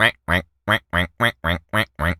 duck_quack_seq_long_03.wav